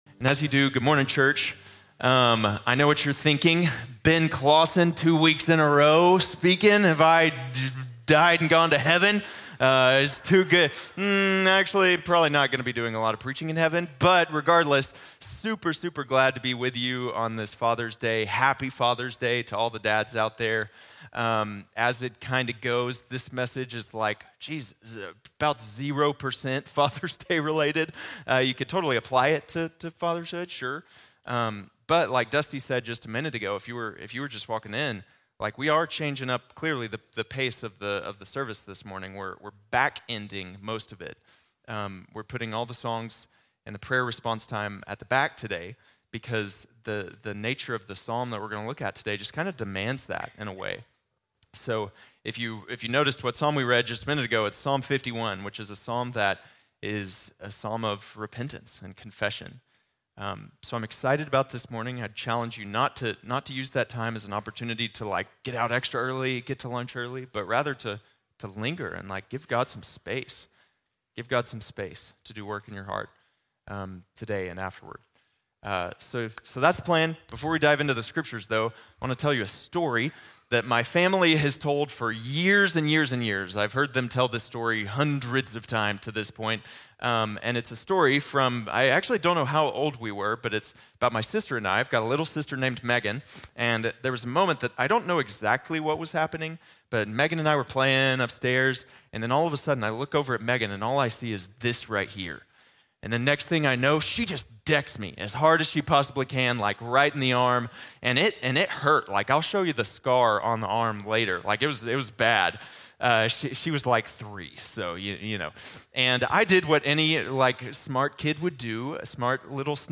Do’s & Don'ts of Dealing with Sin | Sermon | Grace Bible Church